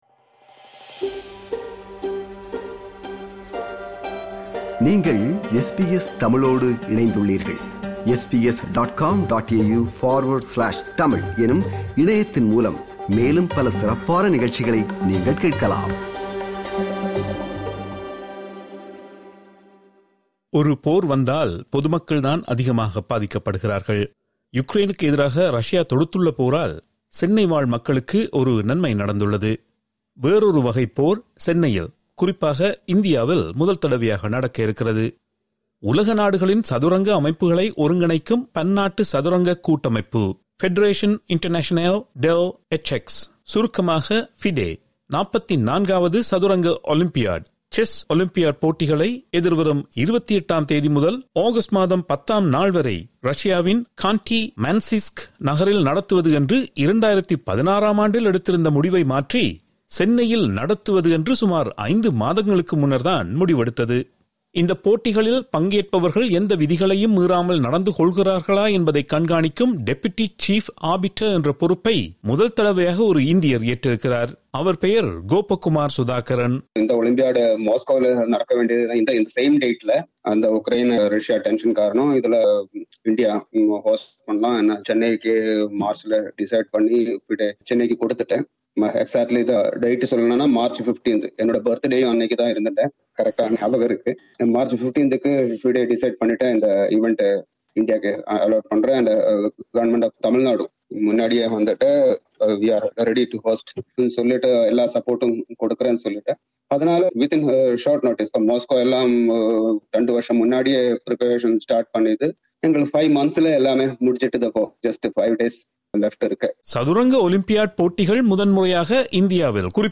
சென்னையில் உலக செஸ் போட்டி! எப்படி சாத்தியமாகிறது? - விவரணம்